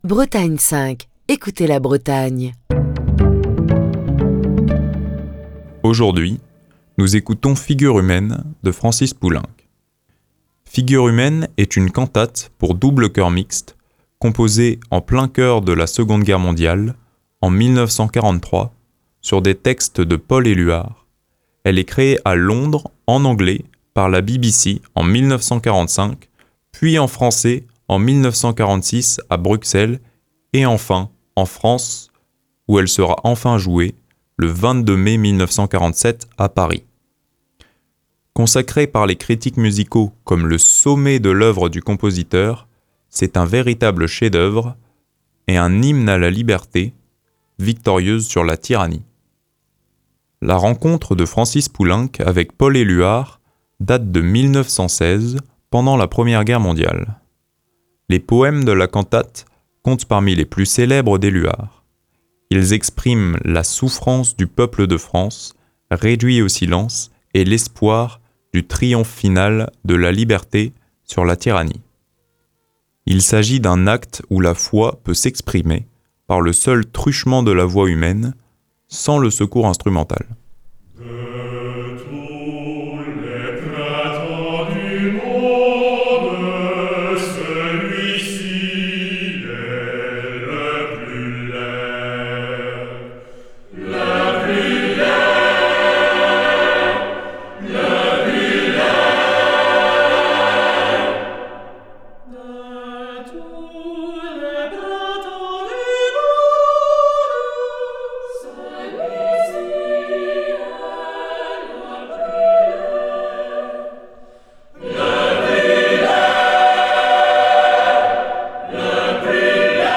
cantate pour double chœur mixte
présente un rythme plus prononcé que les autres chants